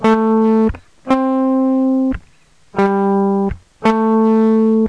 Le guitariste électrique - Exercice d'oreille 1
Solution : La, Do, Sol et La